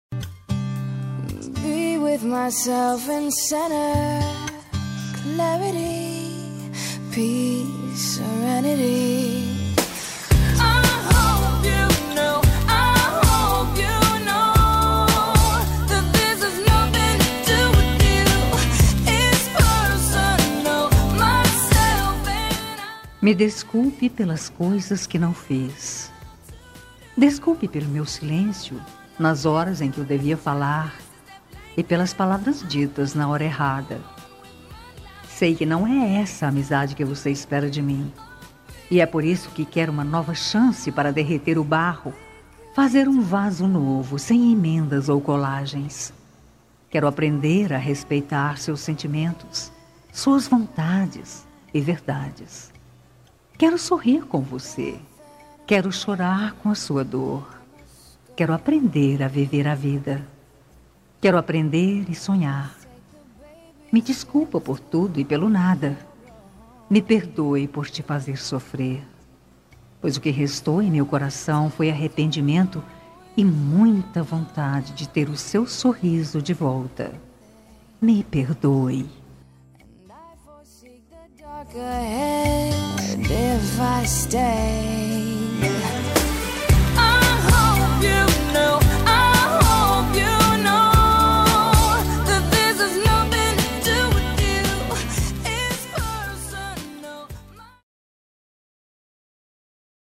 Telemensagem Amizade – Voz Feminina – Cód: 31321 – Desculpa